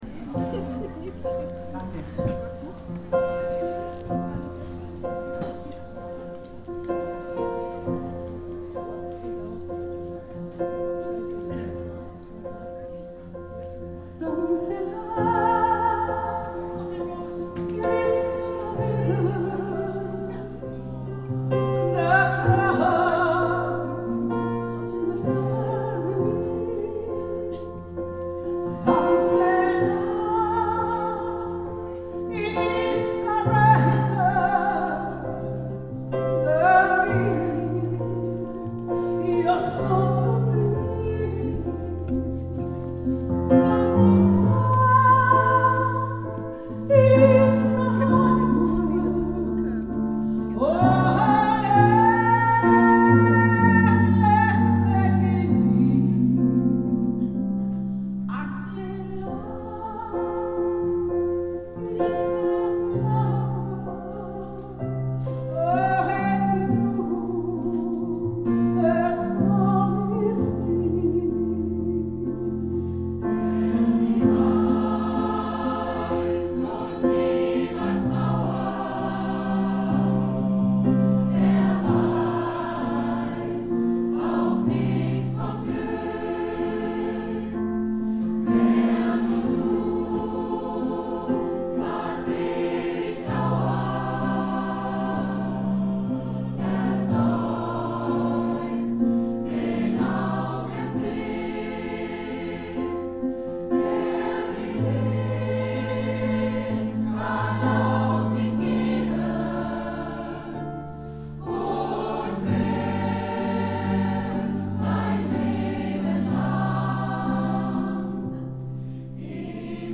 09 - Konzertaufnahmen - ChorArt zwanzigelf - Page 3
Wir sind laut, leise, kraftvoll, dynamisch, frisch, modern, bunt gemischt und alles, nur nicht langweilig!